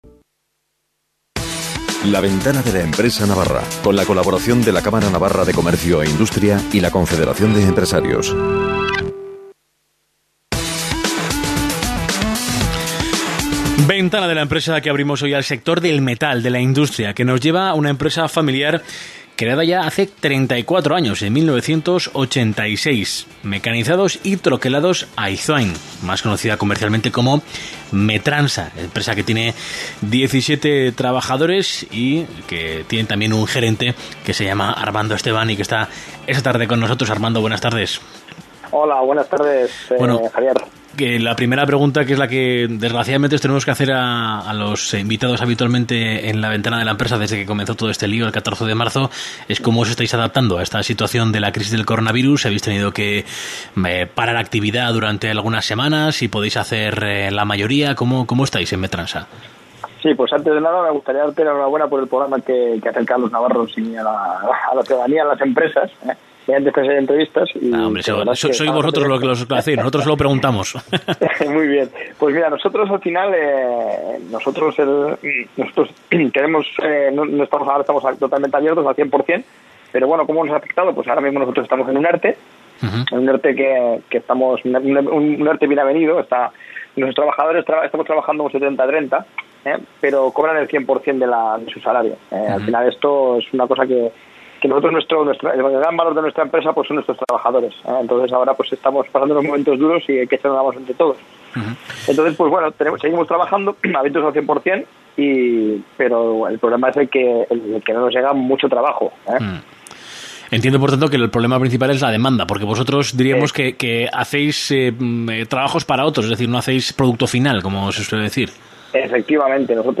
Entrevista en Cadena Ser - Metransa
Este programa se emite todos los jueves a las 19:00 h, la entrevista será telefónica y comenzará a las 19:25 h hasta las 19:40 h. Se trataron temas como la afectación la crisis del Coronavirus a la empresa, nuesrta actividad, retos para el futuro y principales dificultades que nos hemos encontrado.